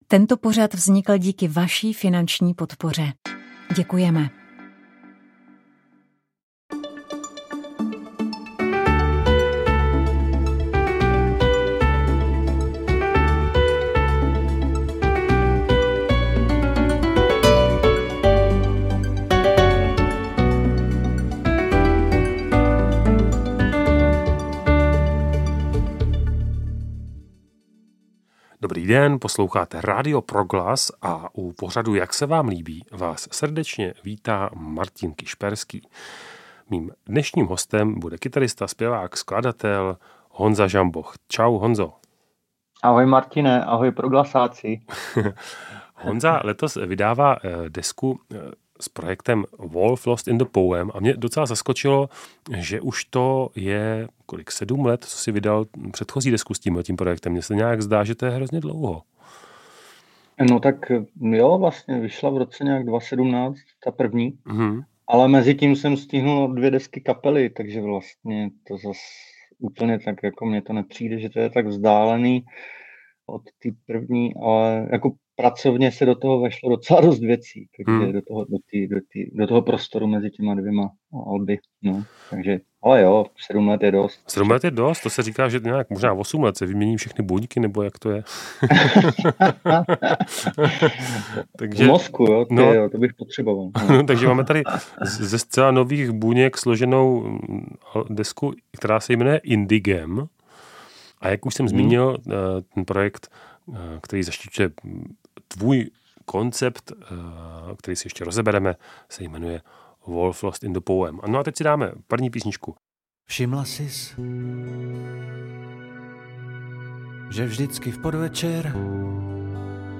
Jak se vám líbí – rozhovor